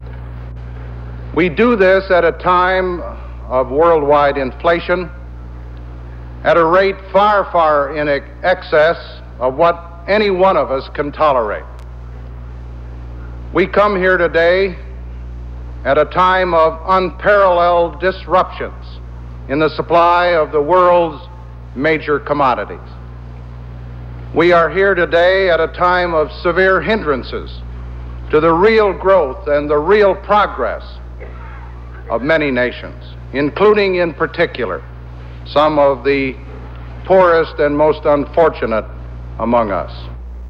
Gerald Ford tells delegates to World Banking and International Monetary Fund conference that they are meeting in troubled times for the world's economy
Broadcast on CBS-TV, September 30, 1974.